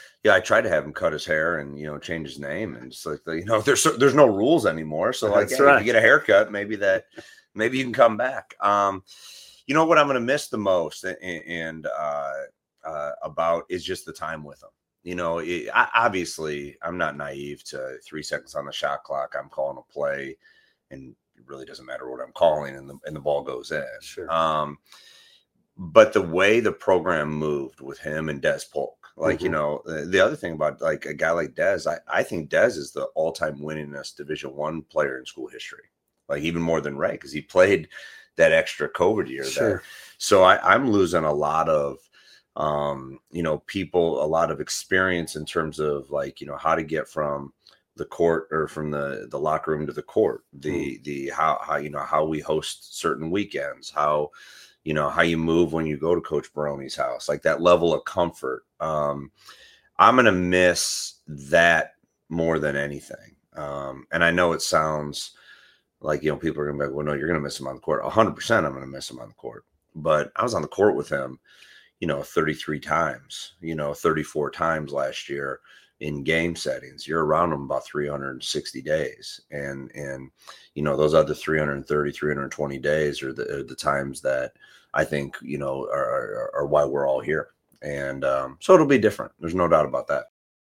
Part One – August Conversation